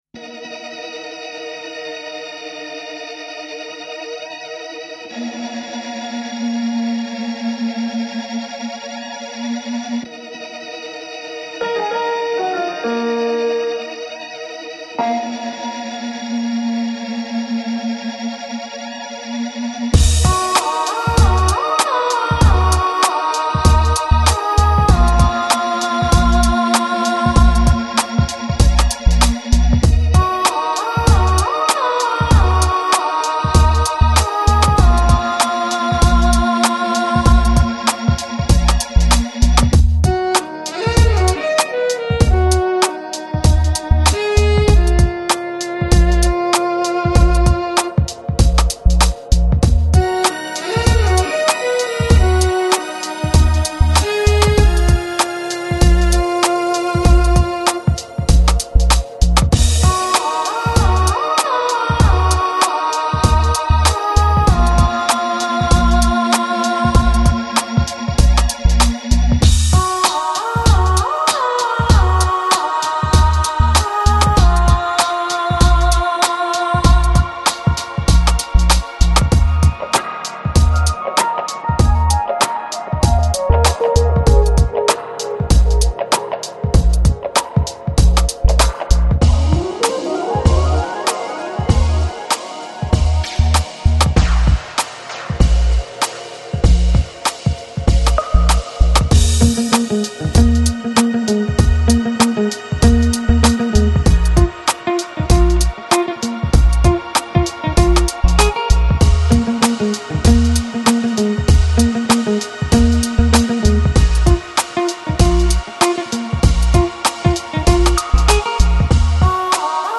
Жанр: Downtempo | House | Chillout